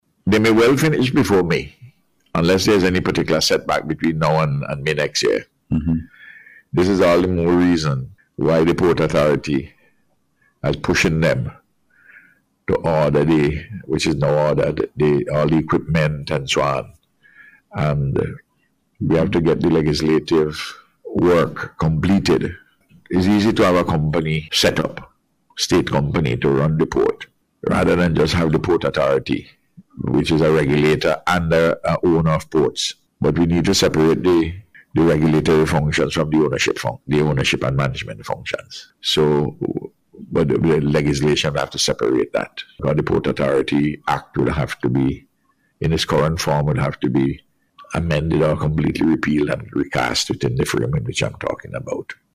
So says Prime Minister Dr Ralph Gonsalves who was providing an update on the progress of the work being done on the project.